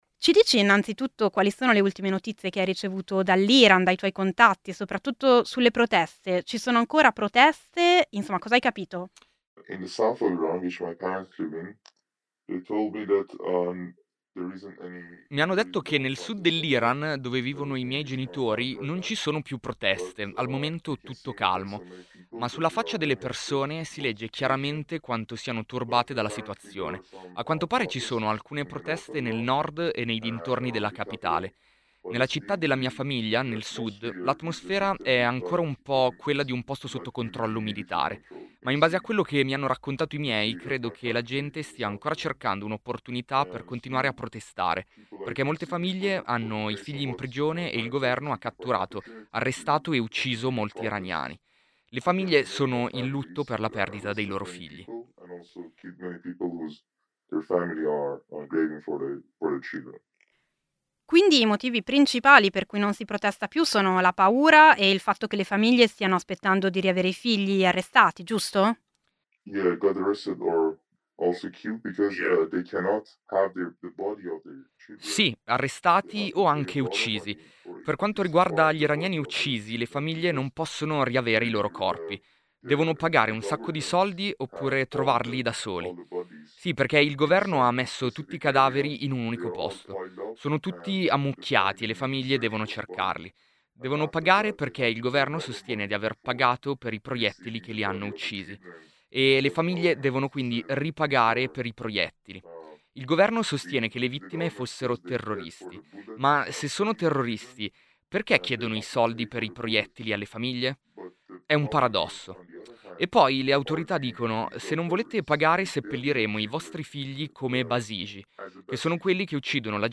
“Per restituire i cadaveri alle famiglie le autorità vogliono soldi” racconta un giovane iraniano a Radio Popolare